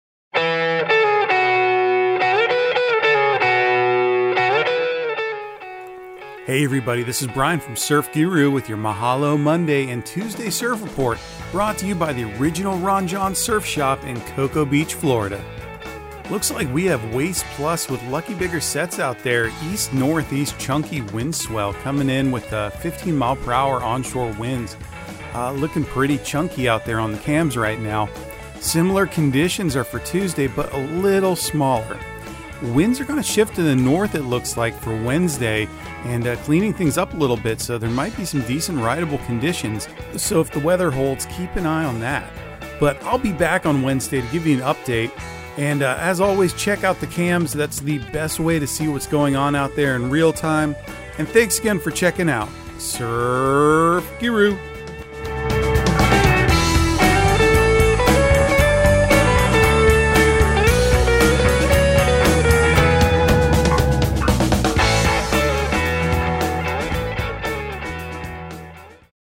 Surf Guru Surf Report and Forecast 11/21/2022 Audio surf report and surf forecast on November 21 for Central Florida and the Southeast.